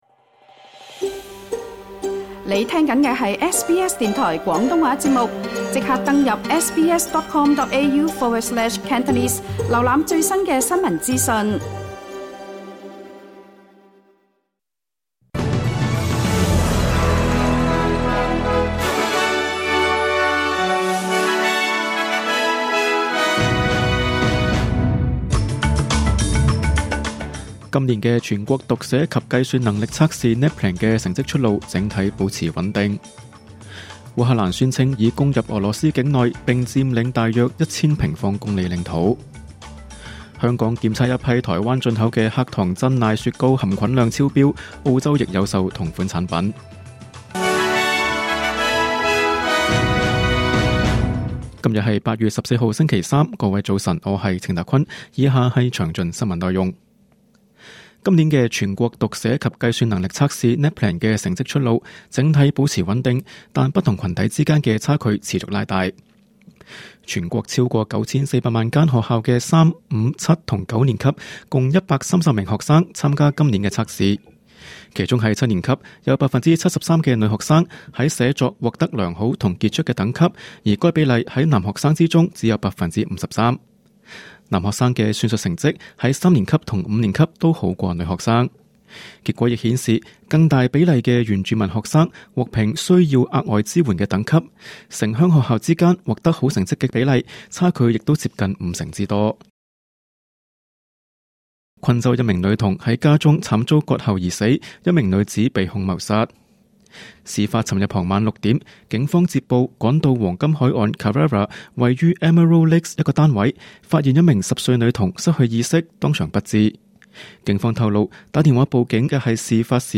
2024 年 8 月 14 日 SBS 廣東話節目詳盡早晨新聞報道。